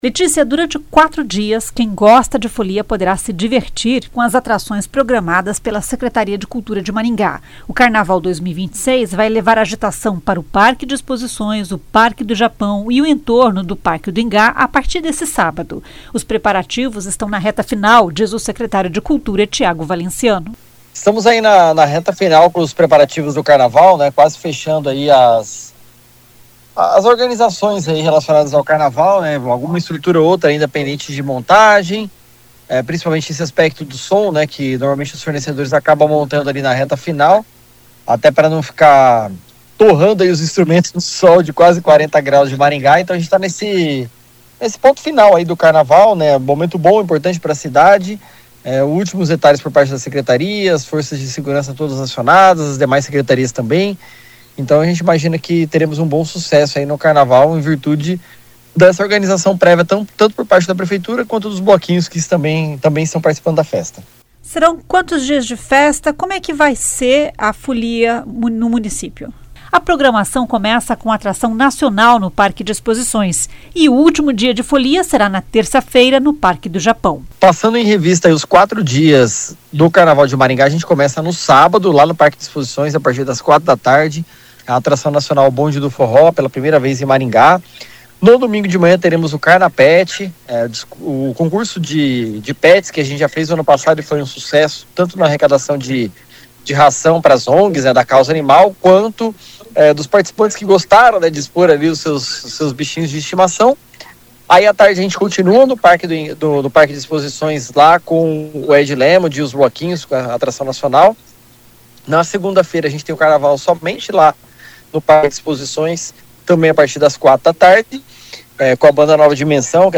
Os preparativos estão na reta final, diz o secretário de Cultura Tiago Valenciano.
O carnaval deve reunir milhares de pessoas, um momento de alegria e diversão, mas que também traz impacto positivo na economia local, diz o secretário.